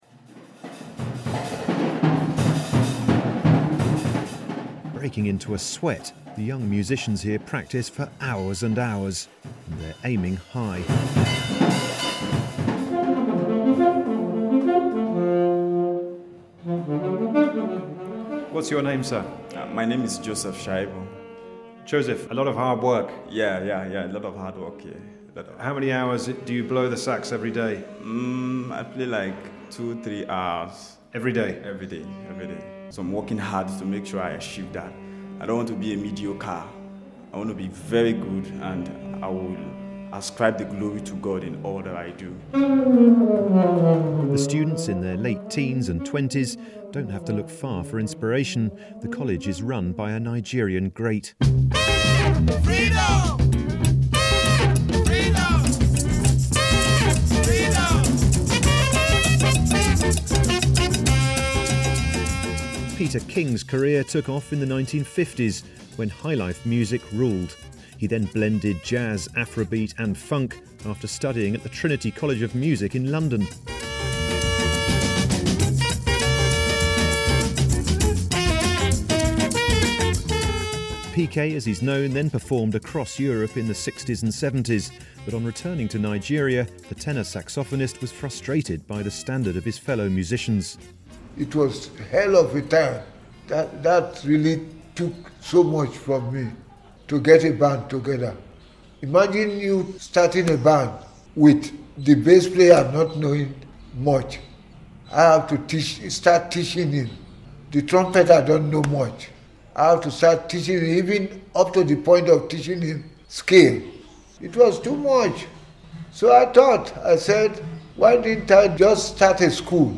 Young Nigerian musicians learning from the great Peter King